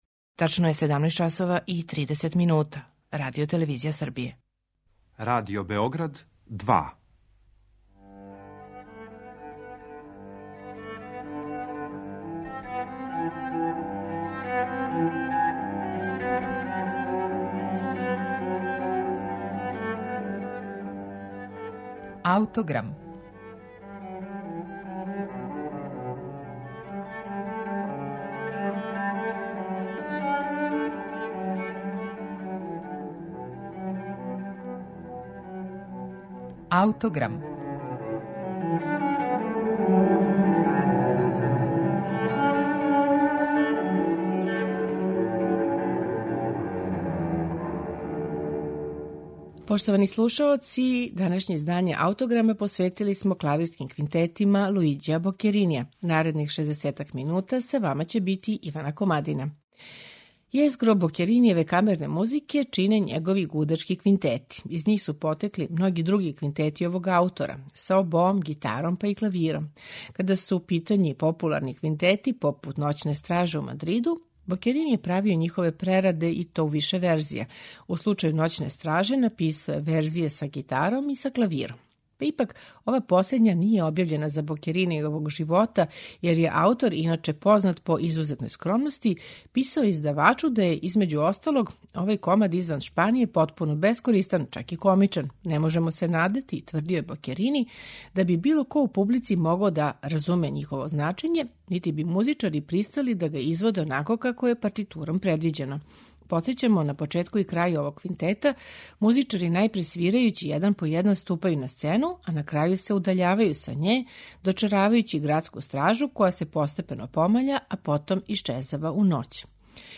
ЛУИЂИ БОКЕРИНИ: КЛАВИРСКИ КВИНТЕТИ
У данашњем АУТОГРАМУ квинтете из ових Бокеринијевих збирки слушамо у интерпретацији ансамбла Claveire, чији чланови свирају на оригиналним инструментима Бокеринијевог доба.